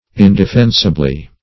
indefensibly - definition of indefensibly - synonyms, pronunciation, spelling from Free Dictionary Search Result for " indefensibly" : The Collaborative International Dictionary of English v.0.48: Indefensibly \In`de*fen"si*bly\, adv. In an indefensible manner.